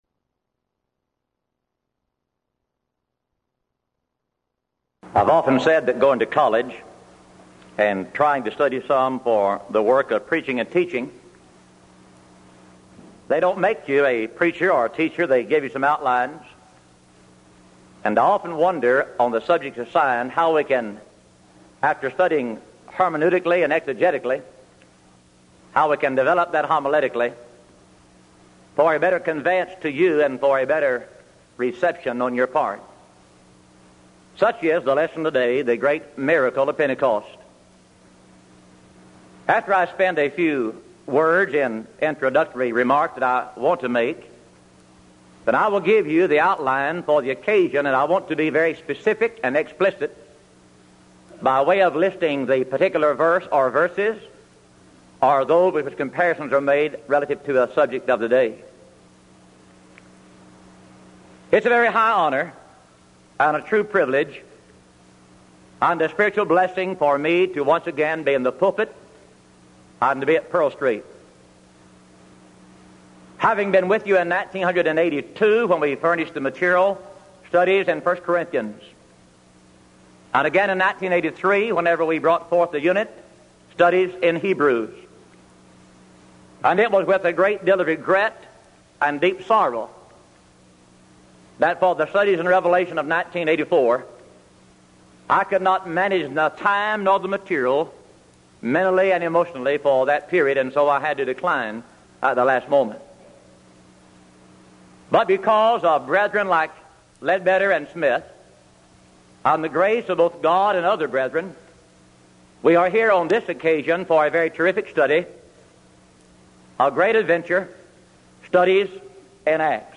Event: 1985 Denton Lectures
lecture